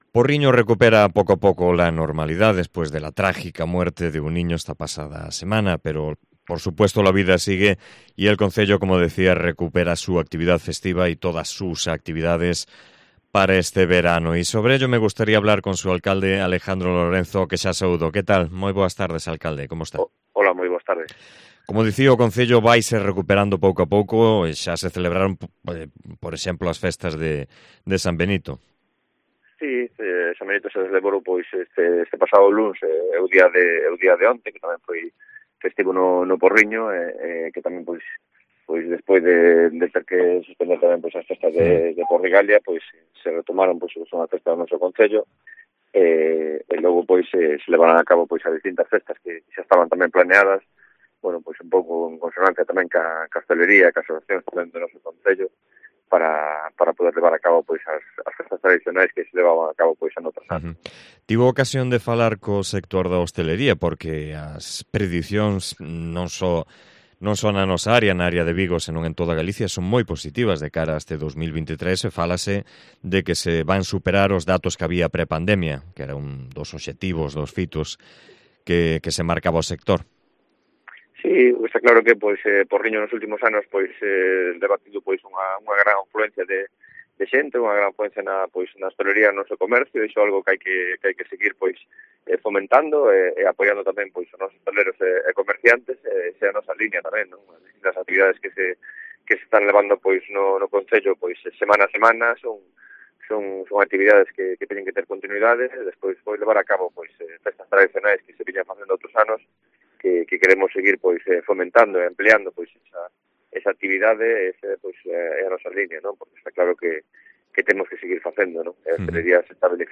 Entrevista a Alejandro Lorenzo, alcalde de Porriño